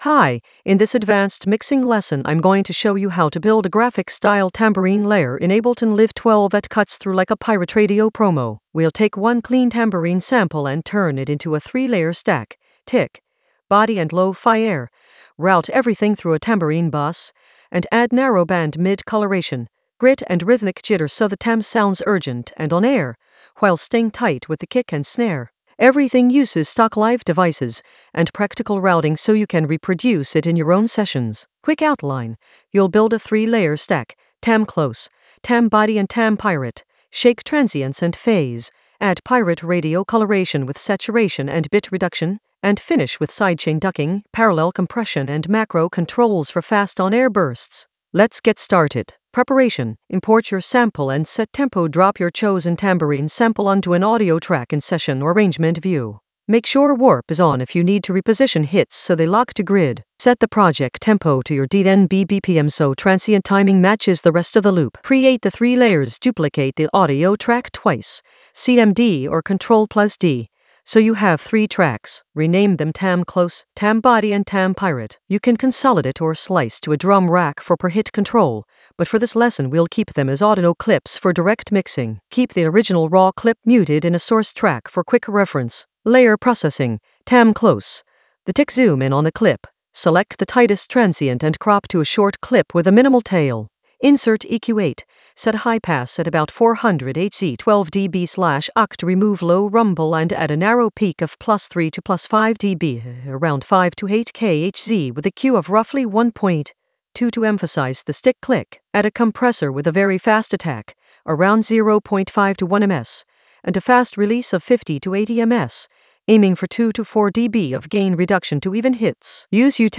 An AI-generated advanced Ableton lesson focused on Grafix tambourine layer: stack and arrange in Ableton Live 12 for pirate-radio energy in the Mixing area of drum and bass production.
Narrated lesson audio
The voice track includes the tutorial plus extra teacher commentary.